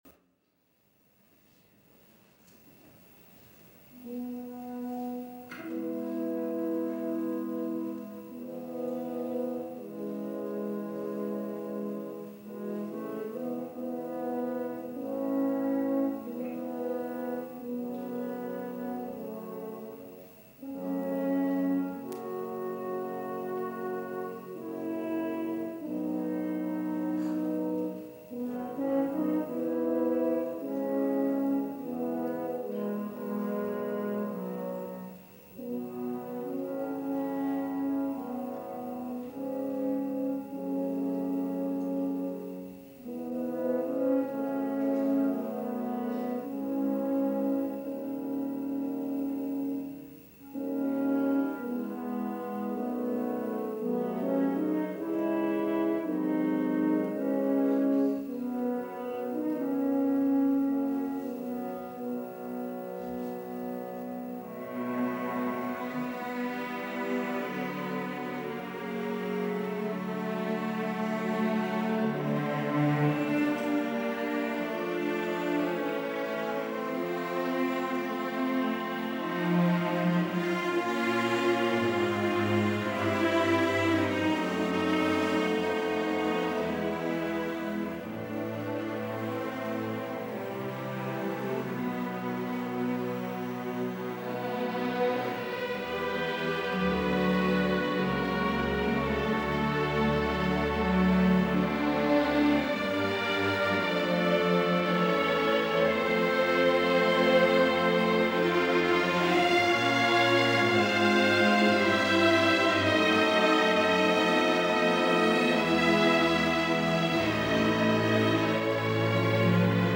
. or click on the link here for Audio Player – L.A. Phil – Steinberg-Ashkenazy – Hollywood Bowl – July 30 1968 – Part 1
Another classic, lost concert this week. A never-before-heard Hollywood Bowl performance by the Los Angeles Philharmonic, guest conducted by William Steinberg and featuring the Russian sensation, Pianist Vladimir Ashkenazy in a performance of the Rachmaninoff Piano concerto Number 3.